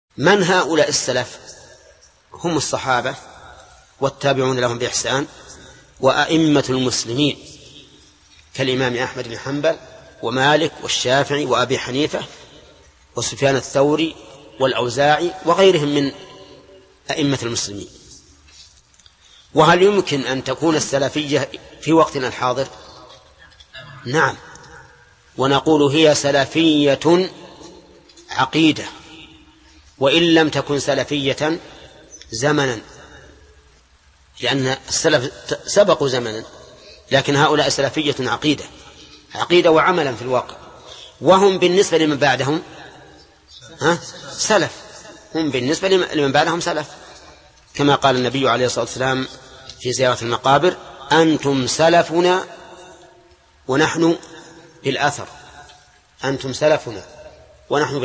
Album: موقع النهج الواضح Track: 6 Length: 0:58 minutes (304.83 KB) Format: MP3 Mono 22kHz 32Kbps (VBR)